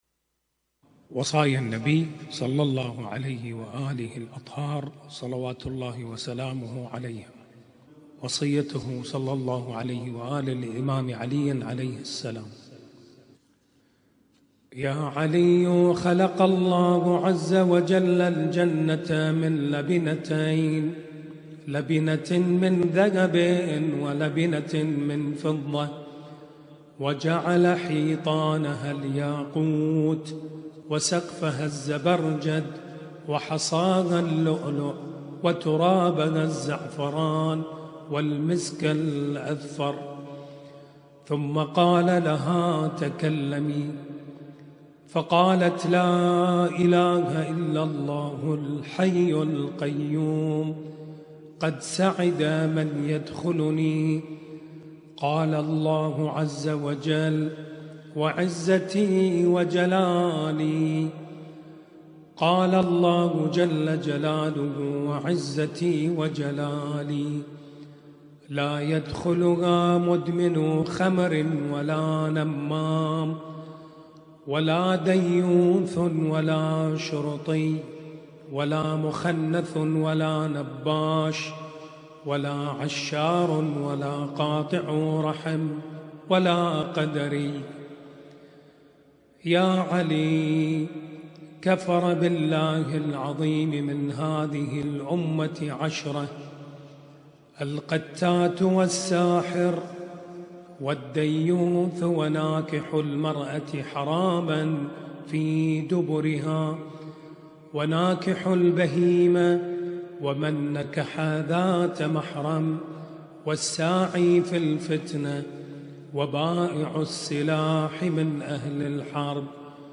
ليلة 11 محرم 1447